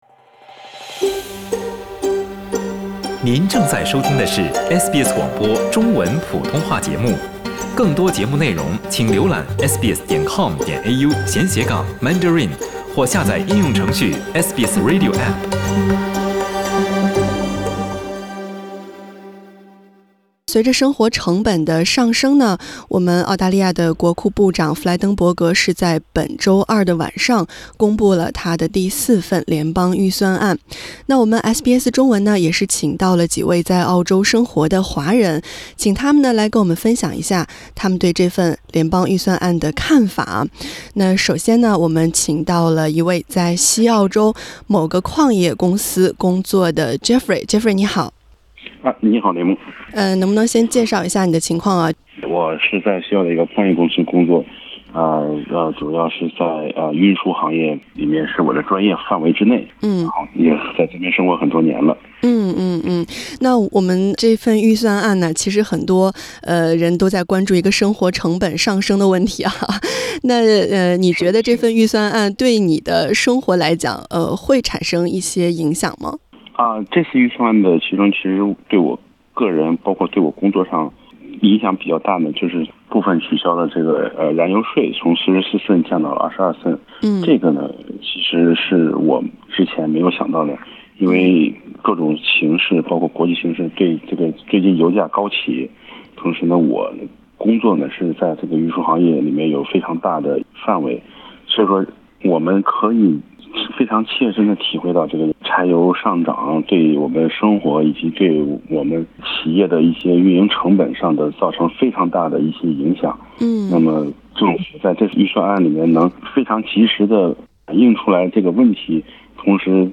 SBS中文分别请到了在澳洲从事不同工作的华人来分享一下他们对这份联邦预算案的看法。